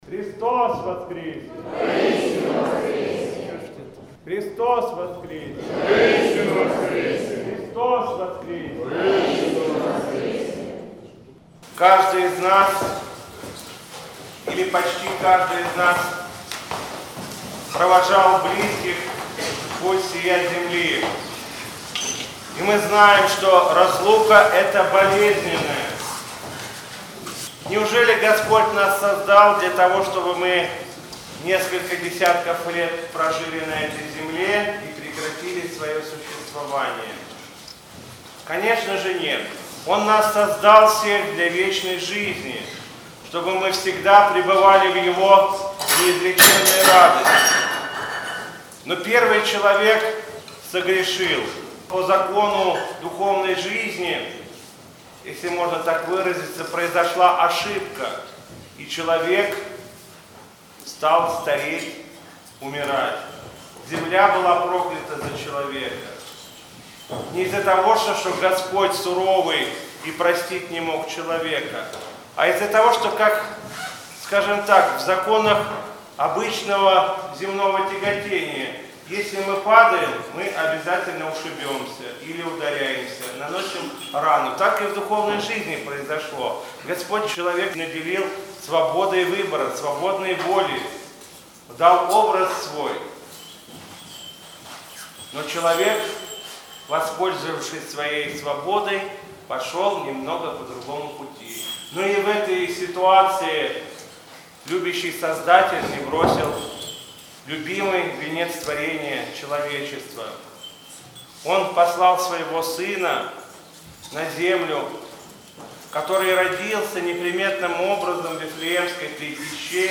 Слово после Литургии на Радоницу